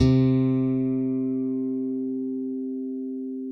ALEM FING C3.wav